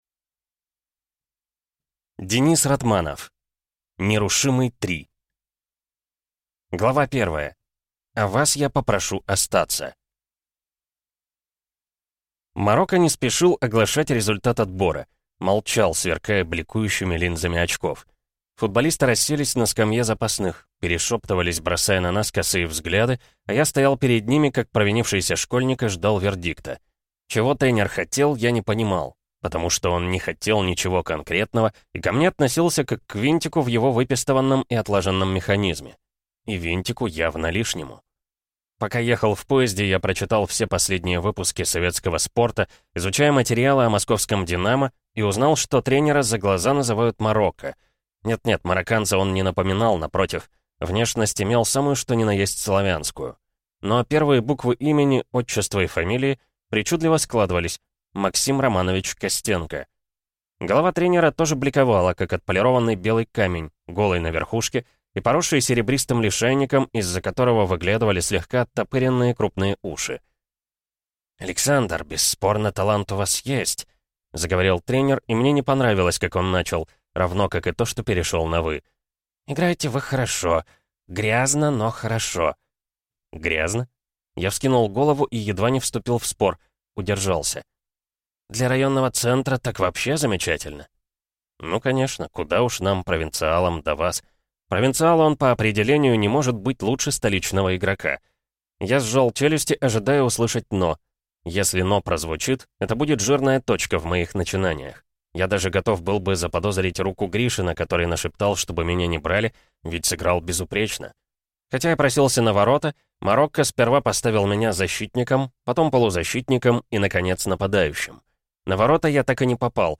Аудиокнига Нерушимый – 3 | Библиотека аудиокниг